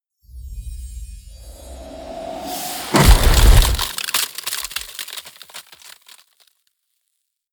Gemafreie Sounds: Whooshes